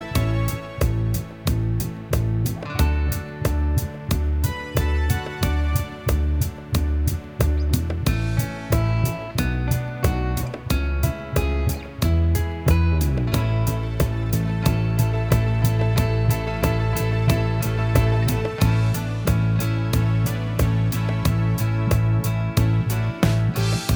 Minus Lead Guitar Pop (1970s) 3:50 Buy £1.50